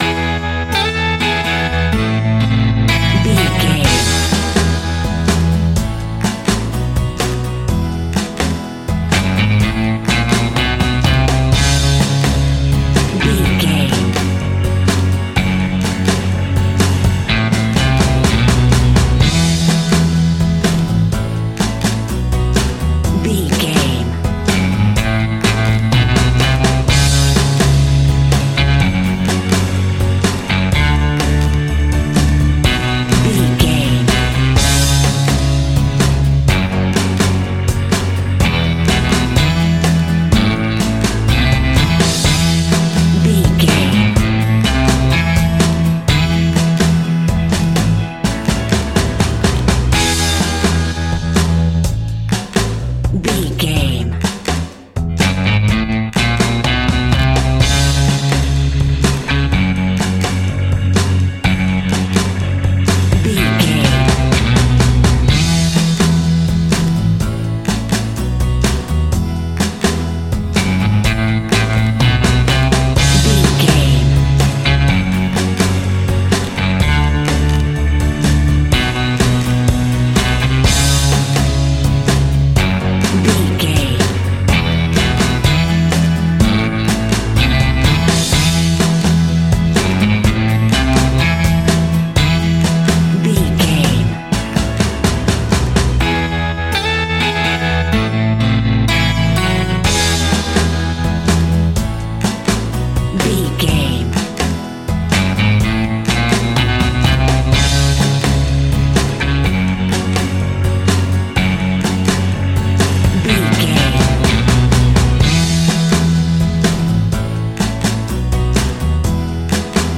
Ionian/Major
fun
energetic
uplifting
cheesy
acoustic guitars
drums
bass guitar
electric guitar
piano
organ